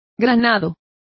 Complete with pronunciation of the translation of pomegranate.